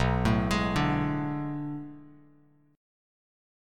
Bm13 chord